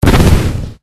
bomb.wav